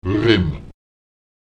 Lautsprecher rem [rEm] während